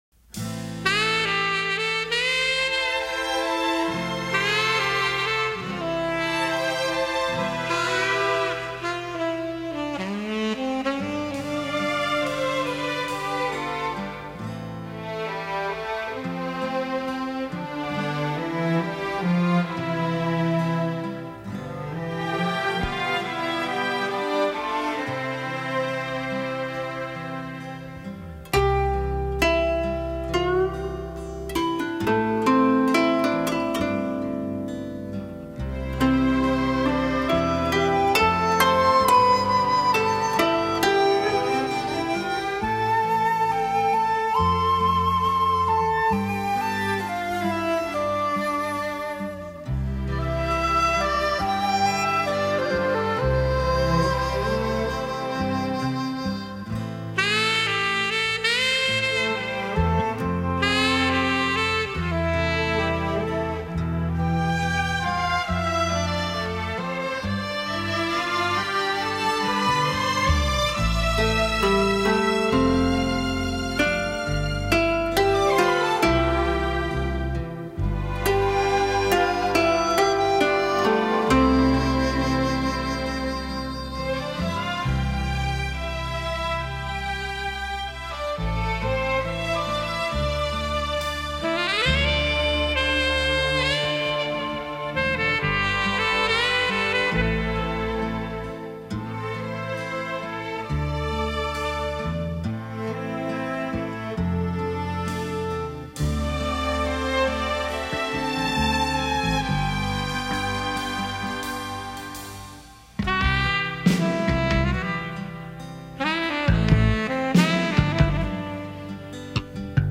箫，又名洞箫，吹奏乐器。
箫音色 柔 和、低音深沉，弱奏最具特色，善于表现乐句悠长、细腻、典雅 的乐曲。